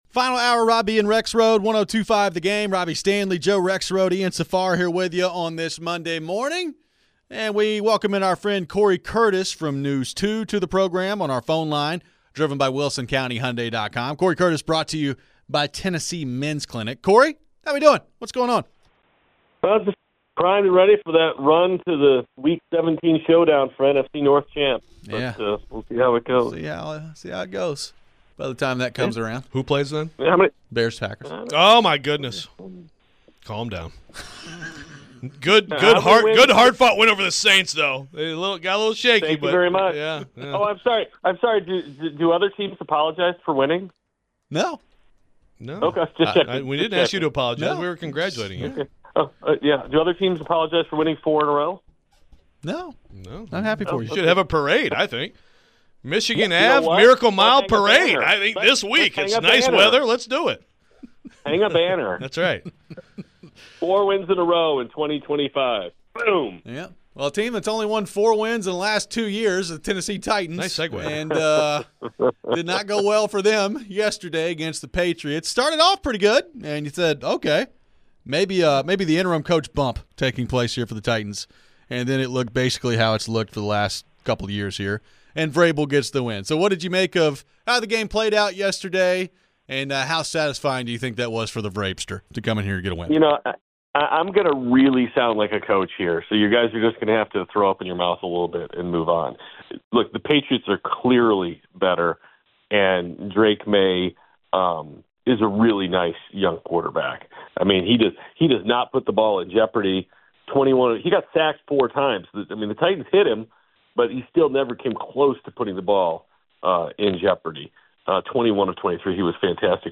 What did he make of the performance and did he sense any improvement from the offense? We get back to your phones on the Titans and Cam Ward.